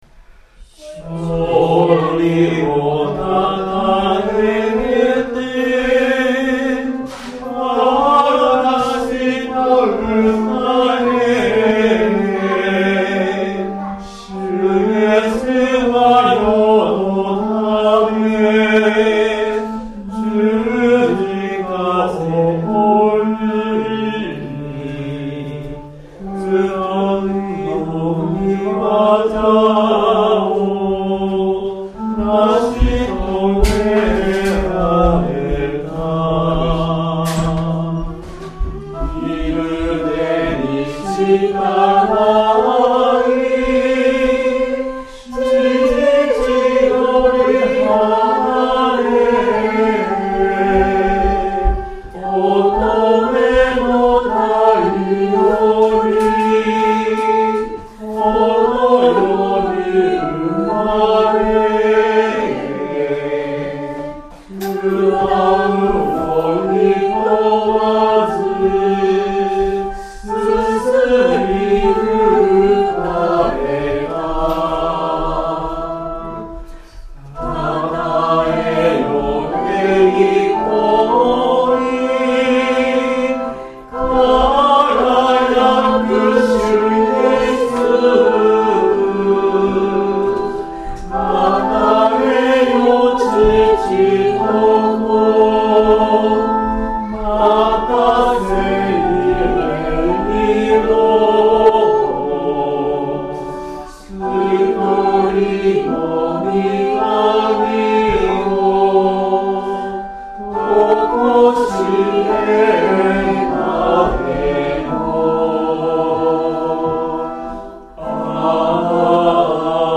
曲：単旋律聖歌 PANGE LINGUA
Temperament = Equal
Sound♪ リバーブ付 Middle Room